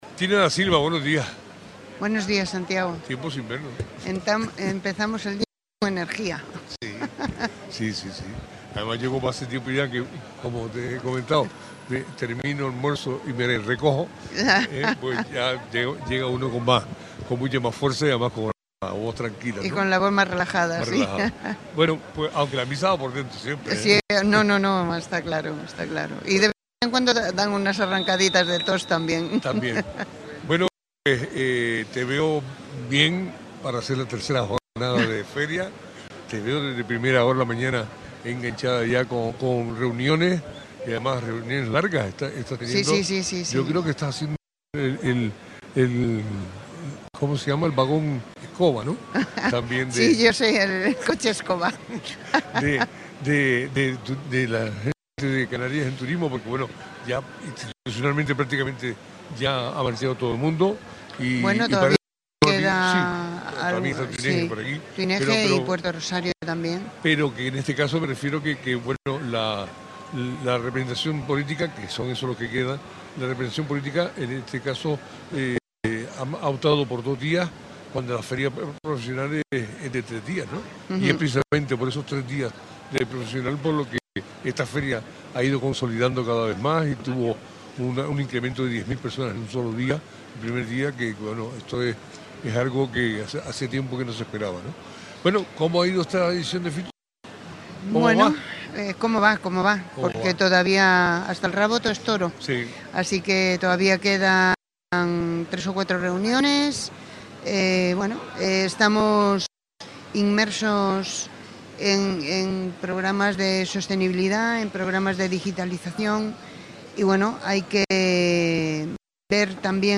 ’Lo tenemos todo, playas, gastronomía, cultura y 365 días para disfrutar de nuestro territorio, recordó esta mañana en Radio Sintonía la concejala de Turismo, Tina Da Silva.
Entrevistas